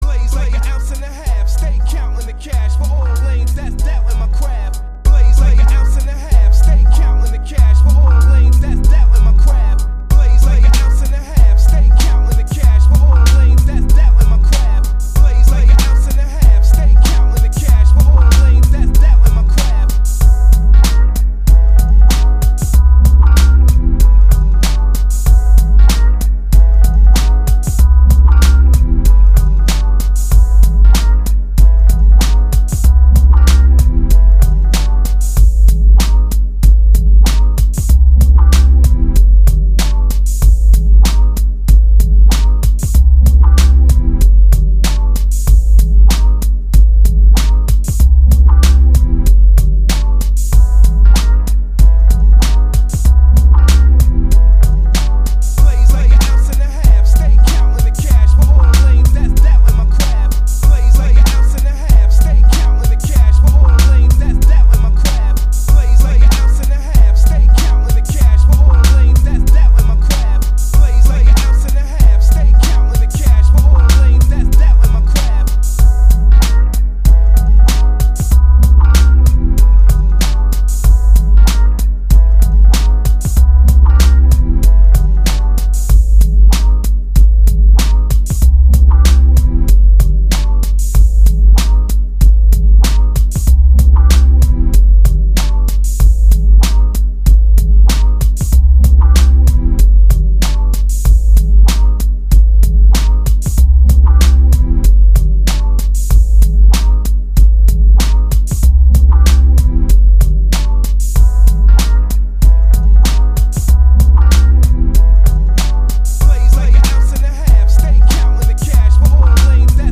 Boom Bap beat with a NY feel